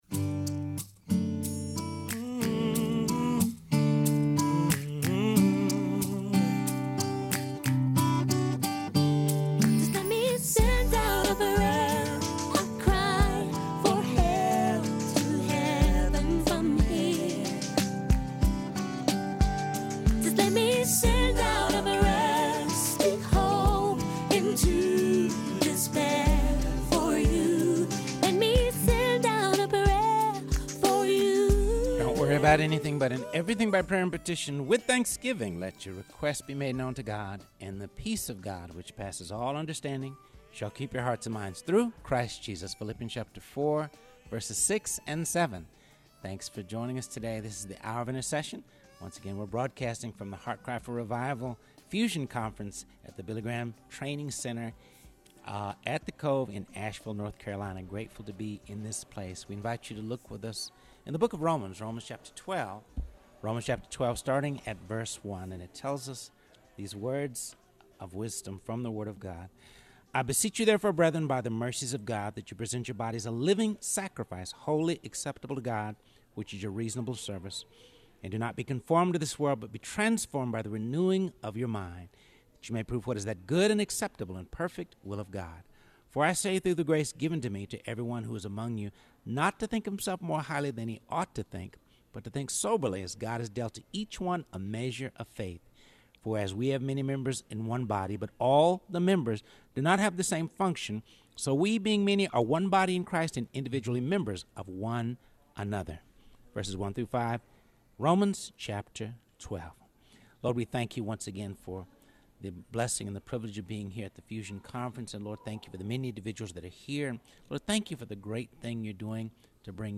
broadcasts LIVE from The Heart Cry for Revival Fusion Conference at the Billy Graham Evangelistic Center in Asheville, North Carolina